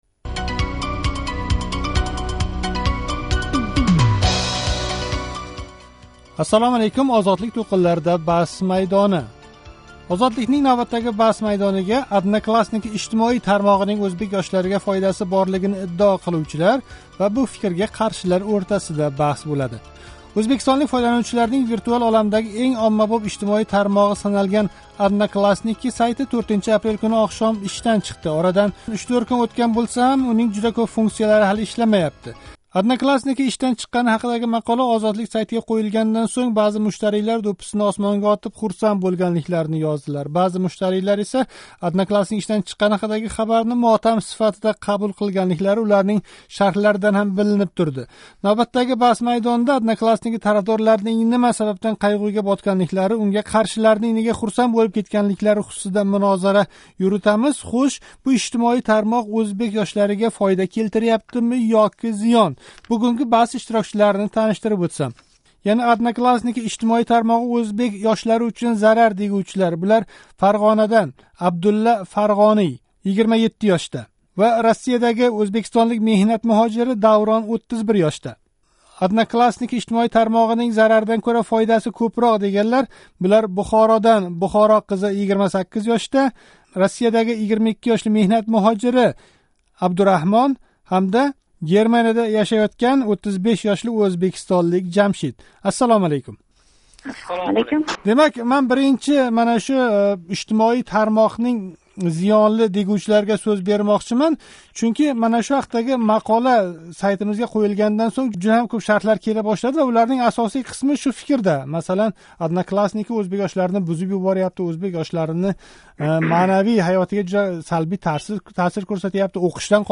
Ozodlikning navbatdagi Bahs maydoniga “Odnoklassniki” ijtimoiy tarmog‘ining o‘zbek yoshlariga foydasi borligini iddao qiluvchilar va bu fikrga qarshilar bahslashdi.